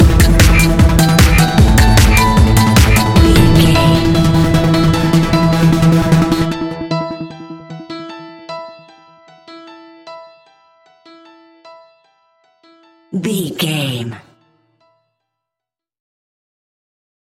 Aeolian/Minor
Fast
futuristic
hypnotic
piano
drum machine
synthesiser
acid house
uptempo
synth leads
synth bass